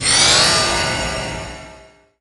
shield_03.ogg